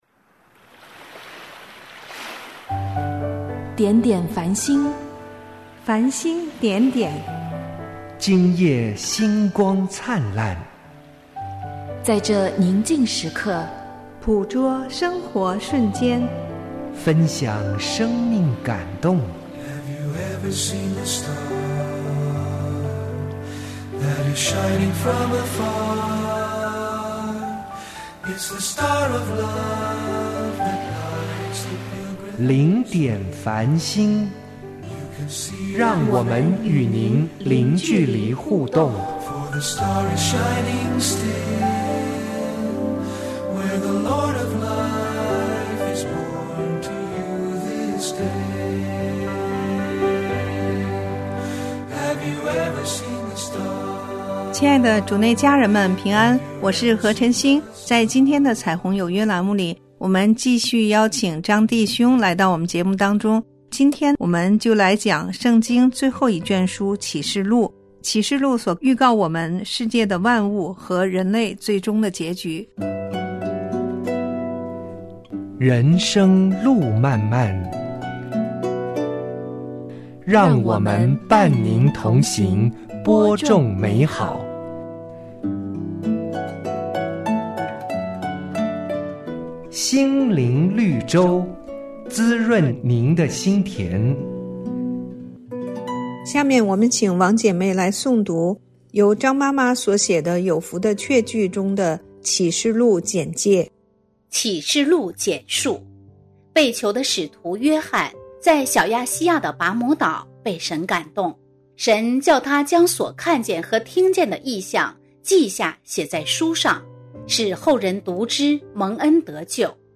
读经分享